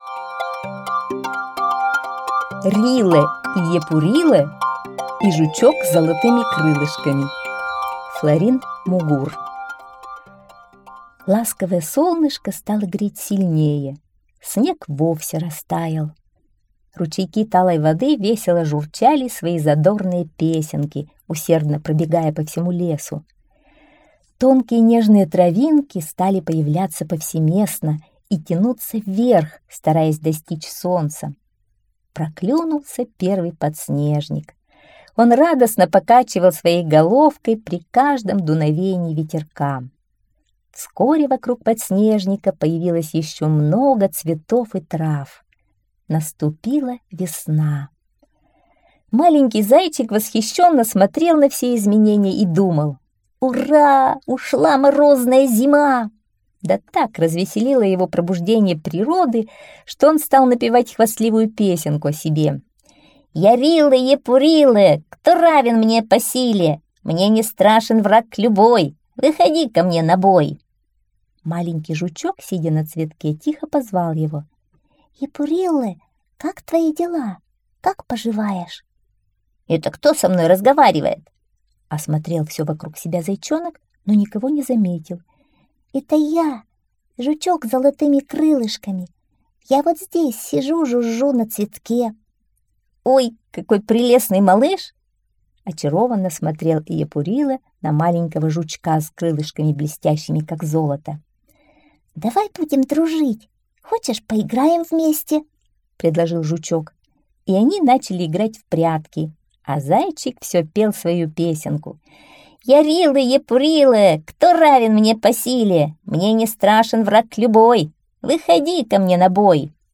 Рилэ-Йепурилэ и жучок с золотыми крылышками - аудиосказка Мугур Ф. Однажды ранней весной маленький зайчик радовался приходу теплых дней...